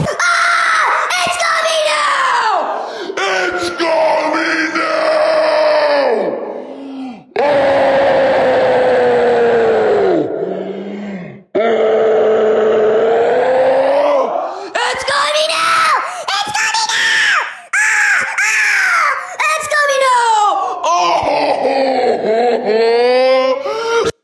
Ahhh Its Coming Out (loud)